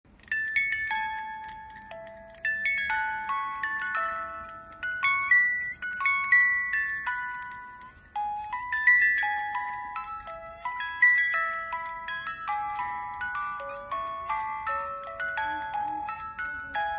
Cajita musical de manivela